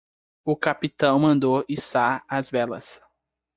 Read more to hoist, to raise Frequency 27k Pronounced as (IPA) /iˈsa(ʁ)/ Etymology Borrowed from French hisser In summary Borrowed from French hisser, from Middle Low German hissen.